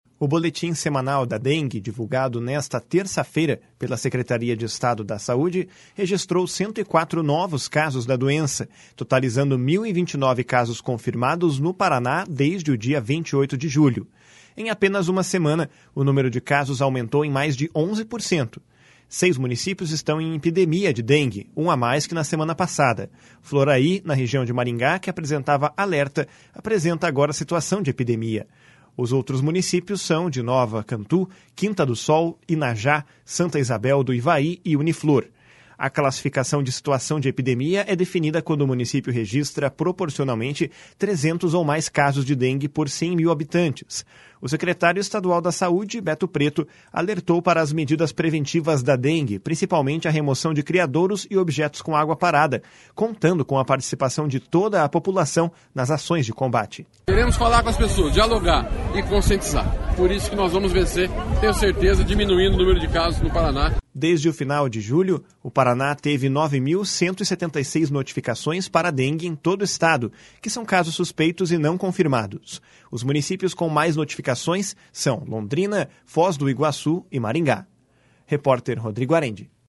O secretário estadual da Saúde, Beto Preto, alertou para as medidas preventivas da dengue, principalmente a remoção de criadouros e objetos com água parada, contando com a participação de toda a população nas ações de combate. // SONORA BETO PRETO //